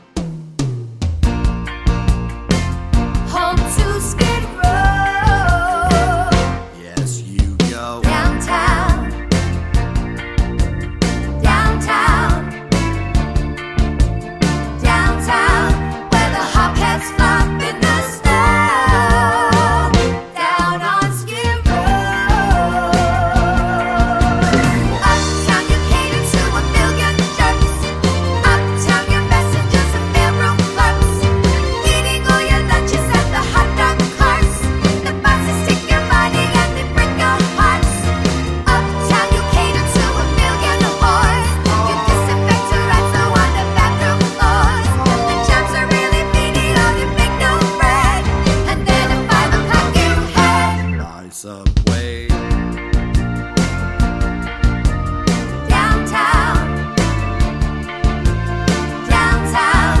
No Backing Vocals Soundtracks 4:16 Buy £1.50